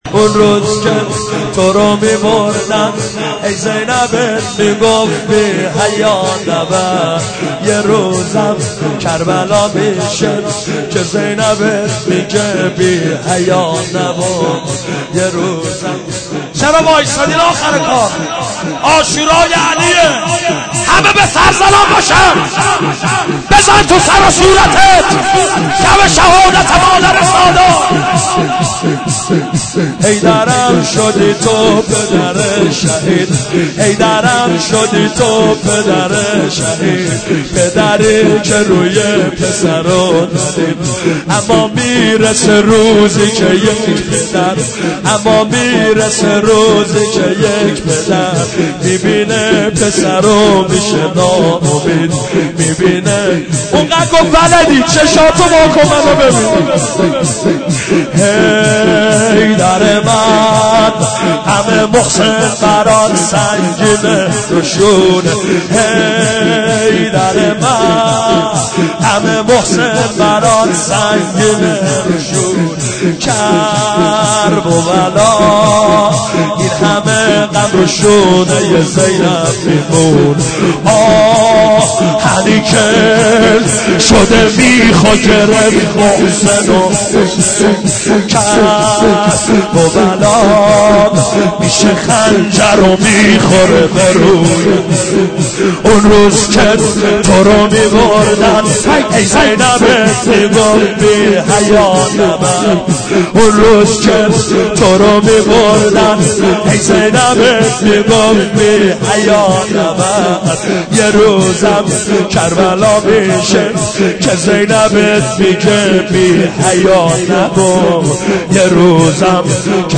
فاطمیه93 هیئت امام موسی کاظم برازجان